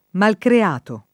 DOP: Dizionario di Ortografia e Pronunzia della lingua italiana
malcreato